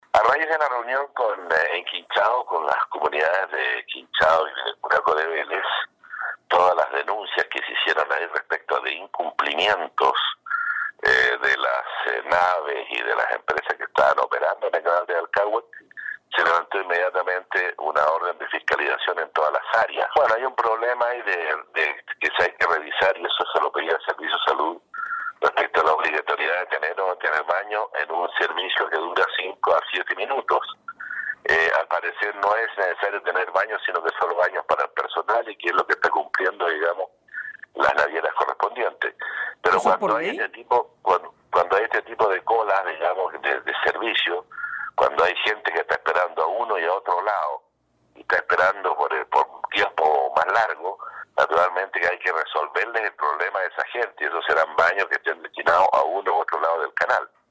Así se desprende por otra parte, de las palabras del intendente Harry Jurgensen, quien planteó estas demandas en el consejo de gabinete ampliado realizado recientemente por el presidente Sebastián Piñera, como lo expresó en entrevista con radio Estrella del Mar de Achao.